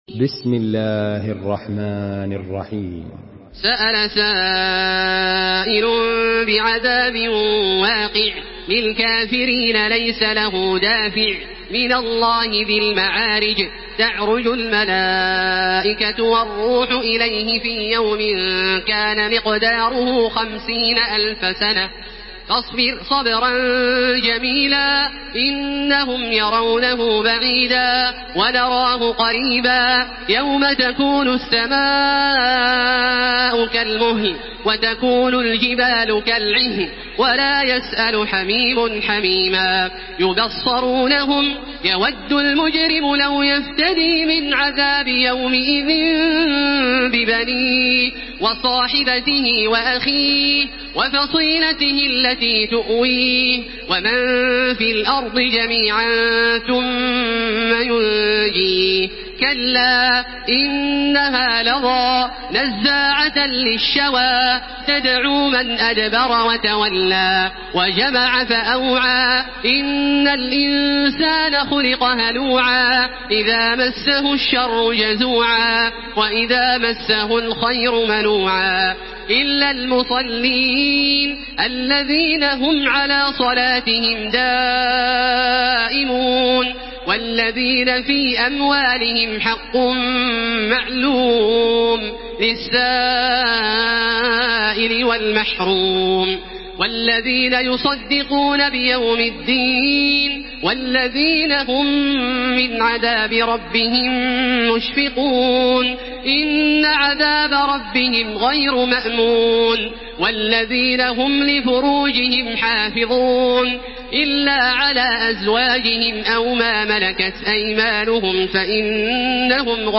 Makkah Taraweeh 1433
Murattal